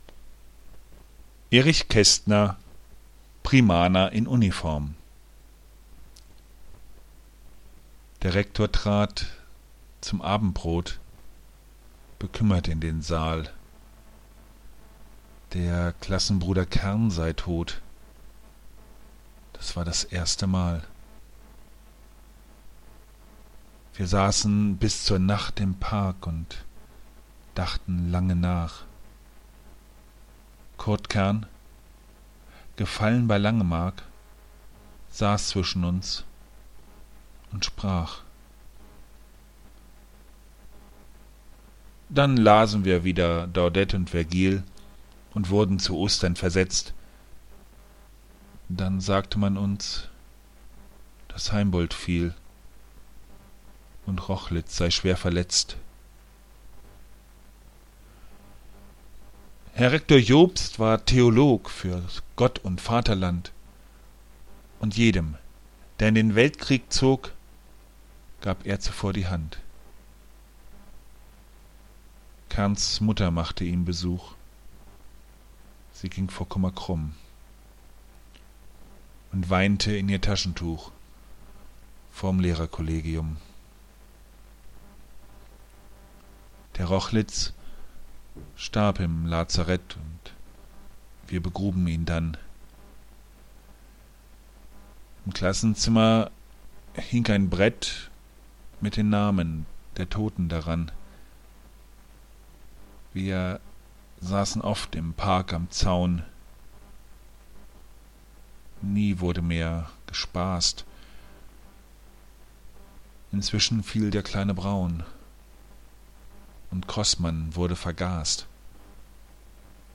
Gedichte, gesprochen